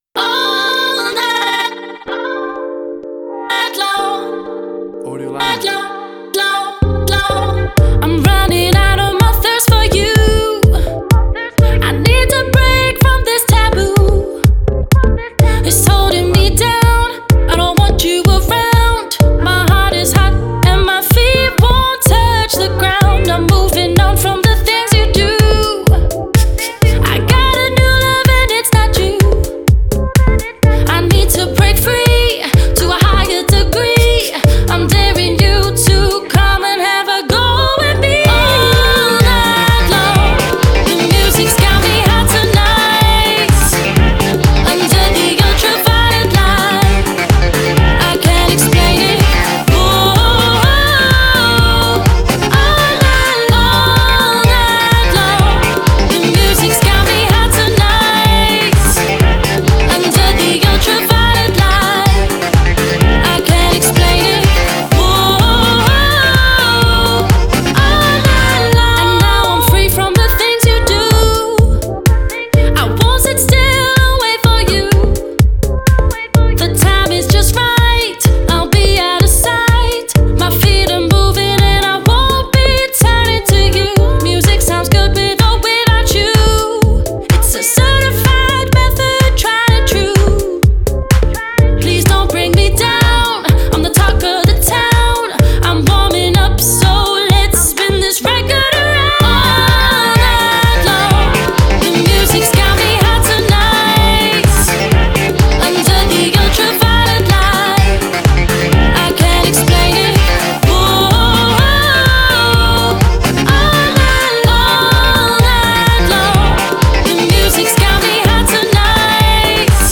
WAV Sample Rate: 16-Bit stereo, 44.1 kHz
Tempo (BPM): 126